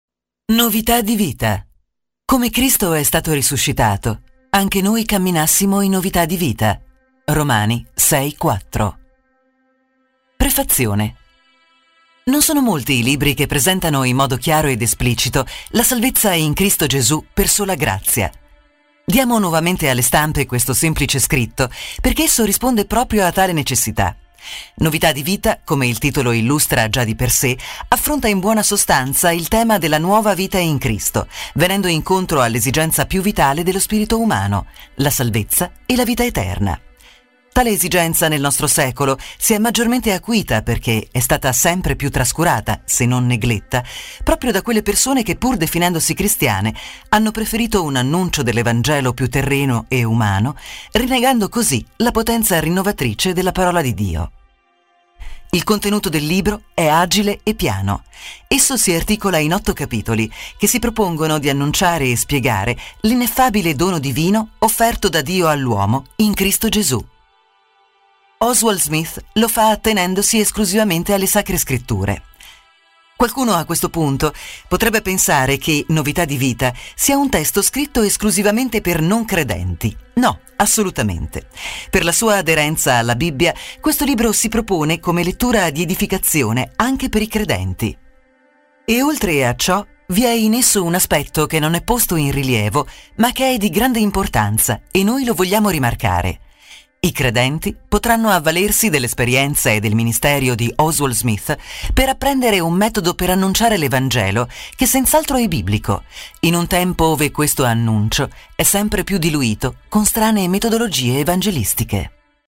Lettura integrale MP3